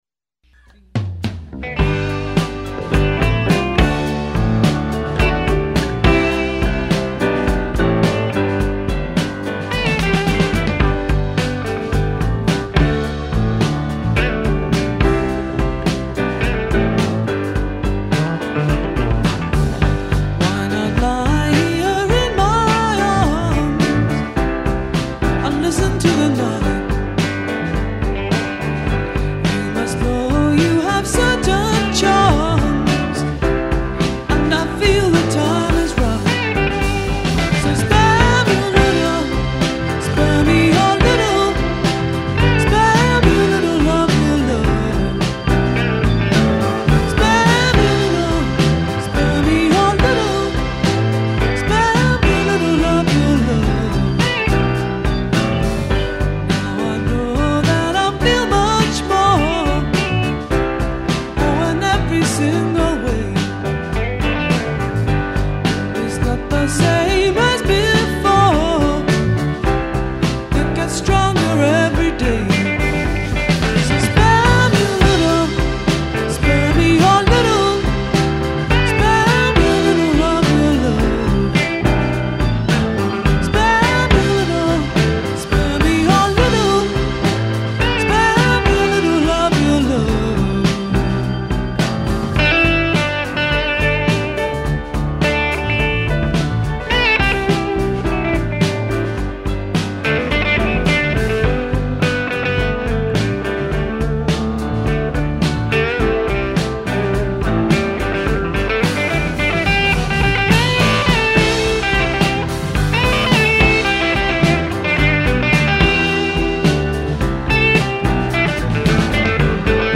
distinctive voice, organ